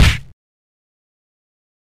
Right Cross punch for a boxing or mma sound effect.